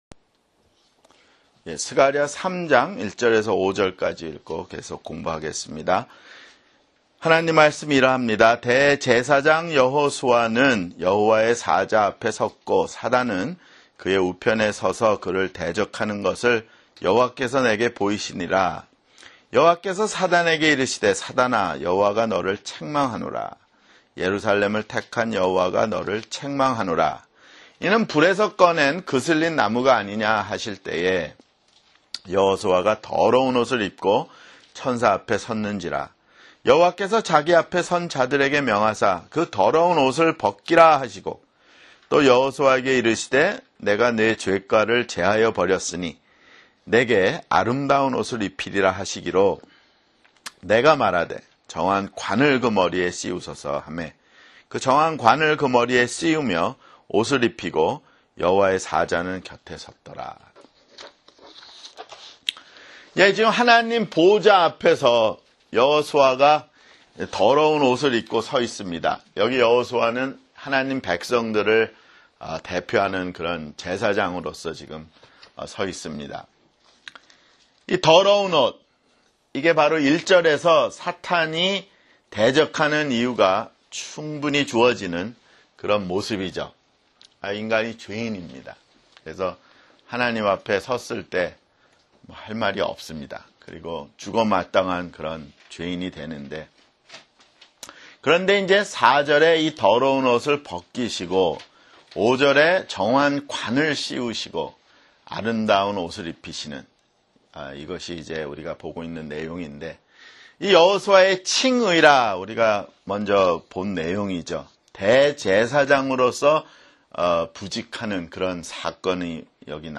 [성경공부] 스가랴 (22)